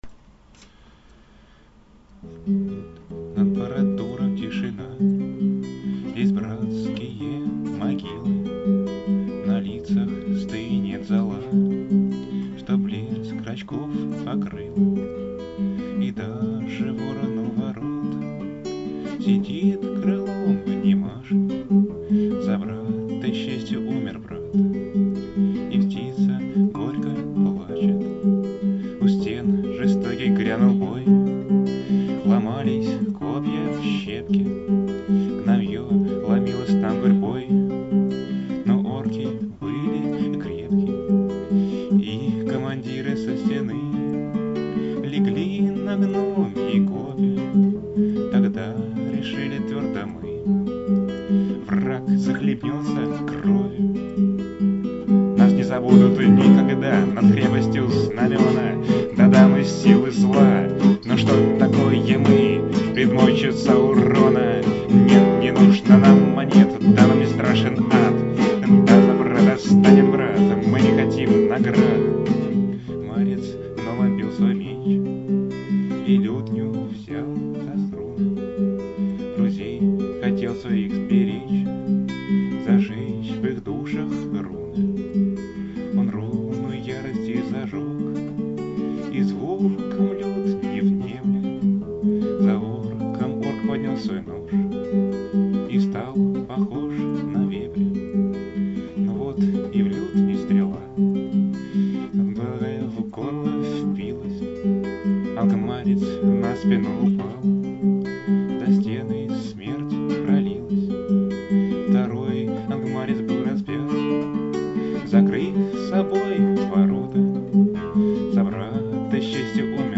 Песни у костра [44]